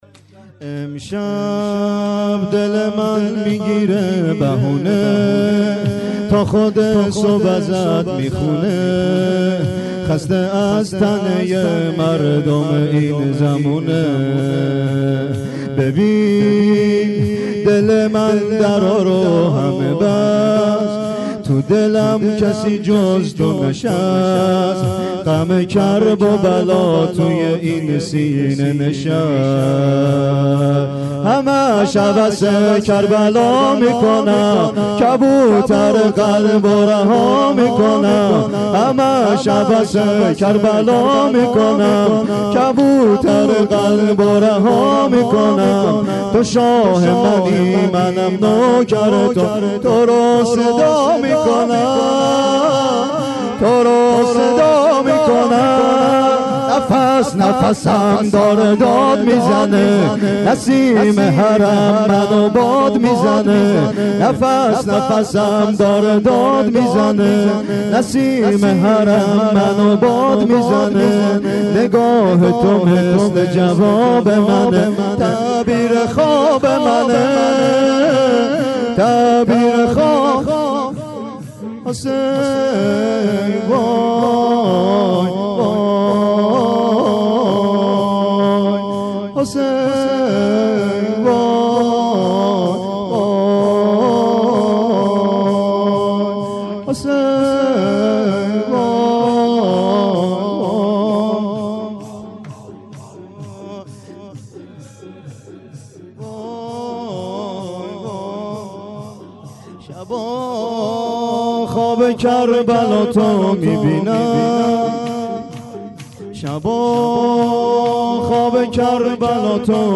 شور همش هوس کربلا میکنم.MP3
شور-همش-هوس-کربلا-میکنم.mp3